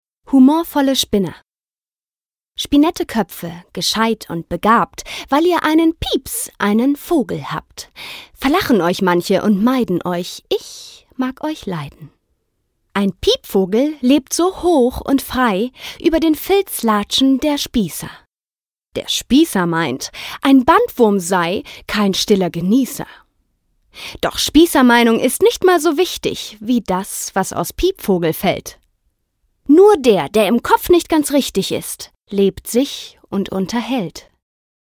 Frau
Sprechprobe: Industrie (Muttersprache):
Fresh, young, warm, rich in variety, sensual or funny, quiet, gentle and charming voice. Comic voices possible. Huge vocal range.